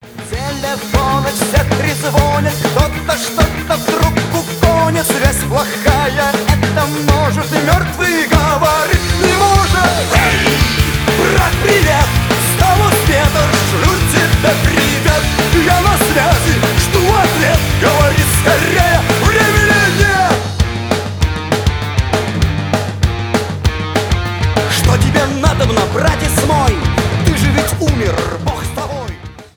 Категория: рок , русские , панк-рок